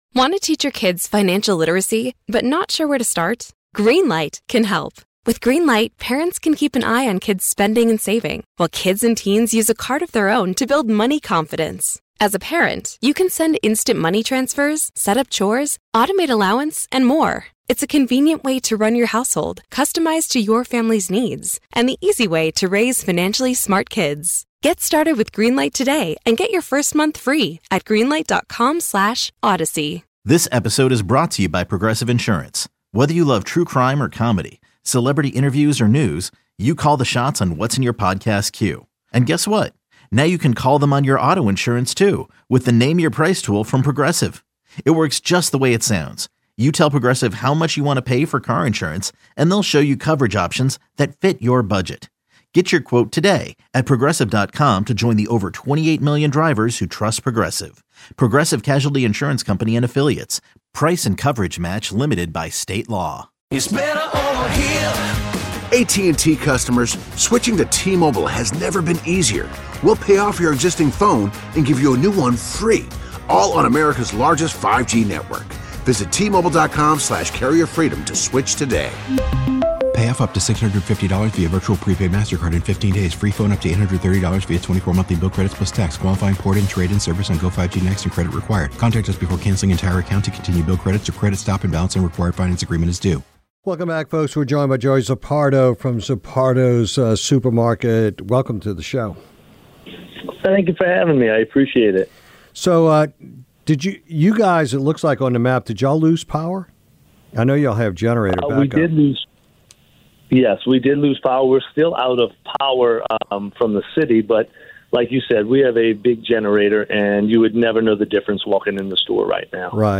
Newell interviewed